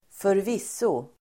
Uttal: [²förv'is:o]